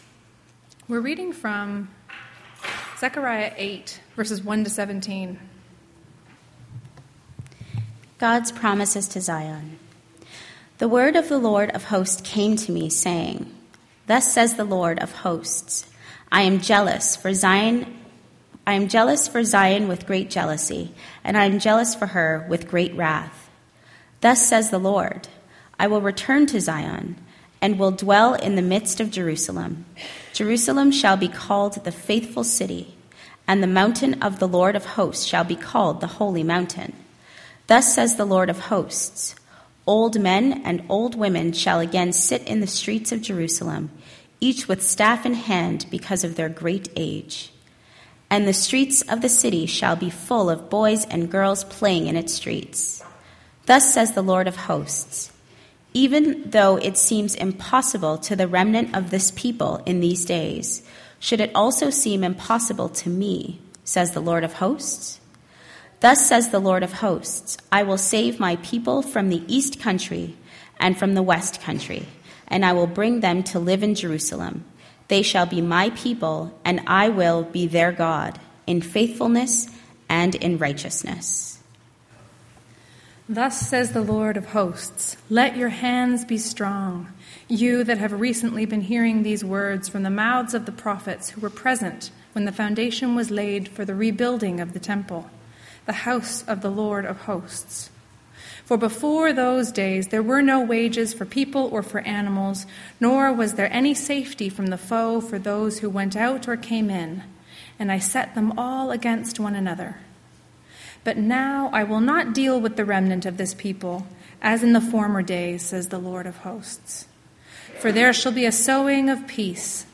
sermon_may08.mp3